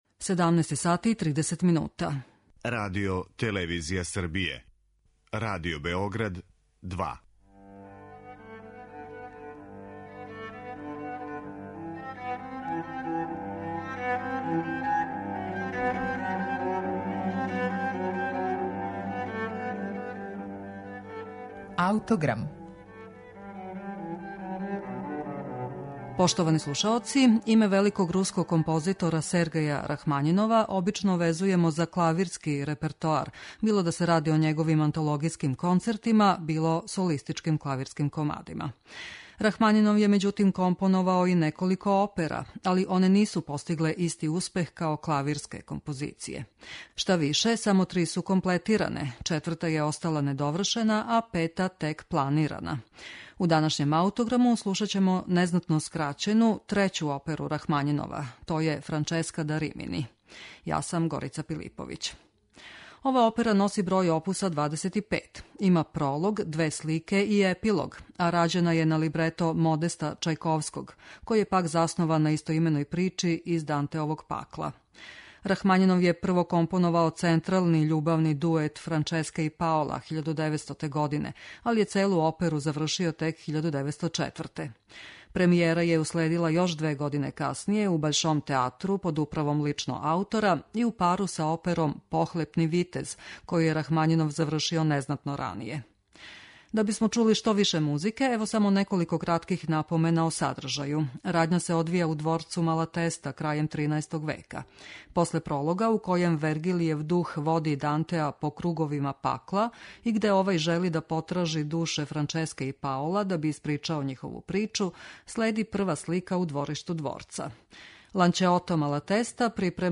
оперу са прологом, две слике и епилогом